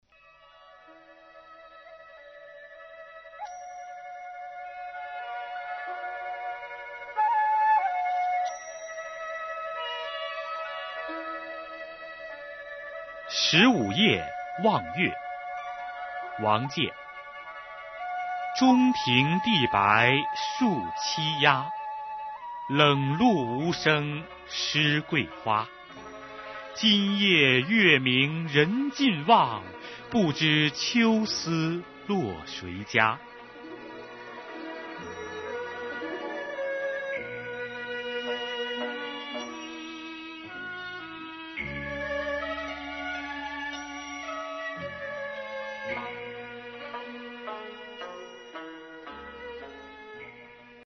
王建《十五夜望月寄杜郎中》原文译文及赏析（含朗读）